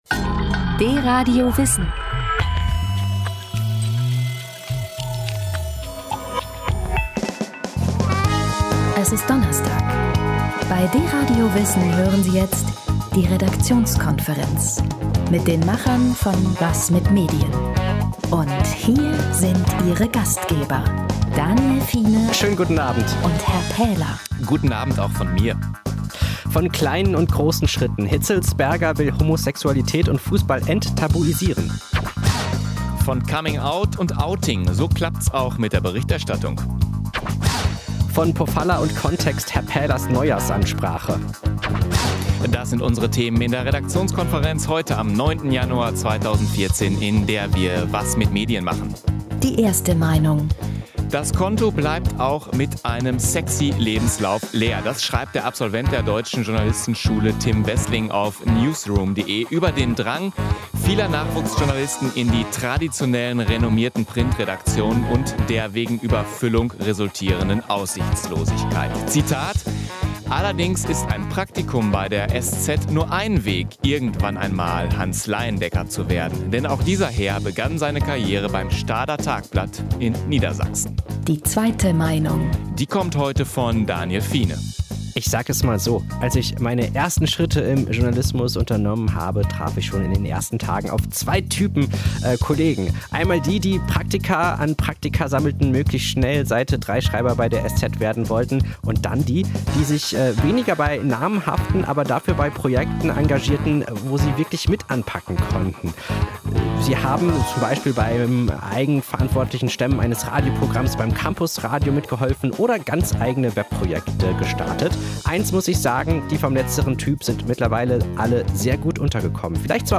Ihr hört eine Sendung von DRadio Wissen.